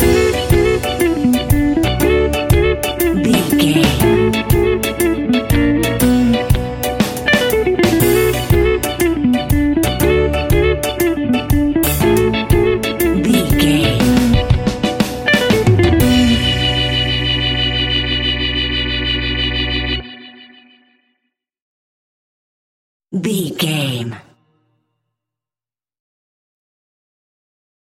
Aeolian/Minor
laid back
chilled
off beat
drums
skank guitar
hammond organ
percussion
horns